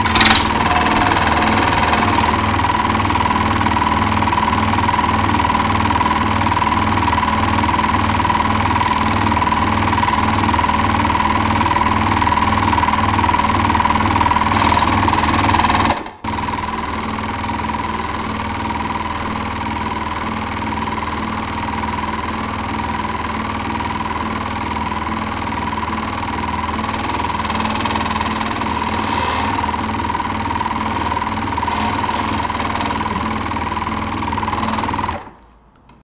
H2oFloss HF 7 два звука один записан рядом другой на расстоянии 3 метров